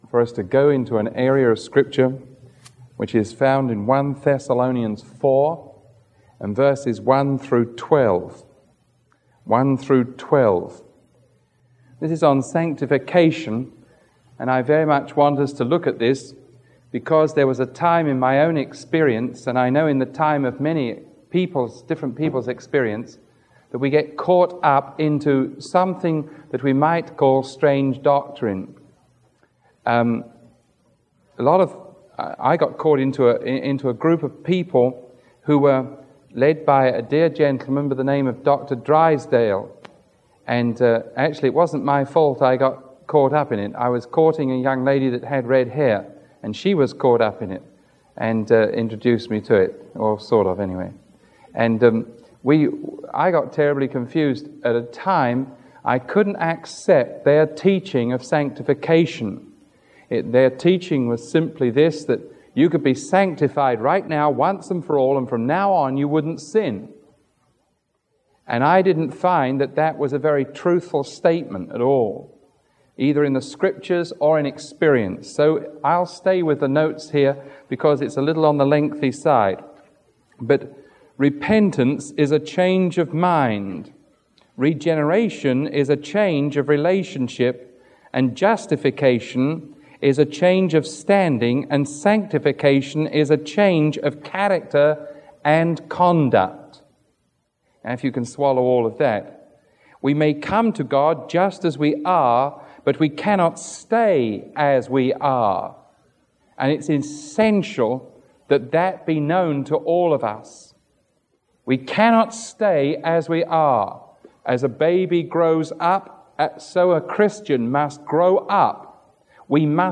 Sermon 0422A recorded on September 23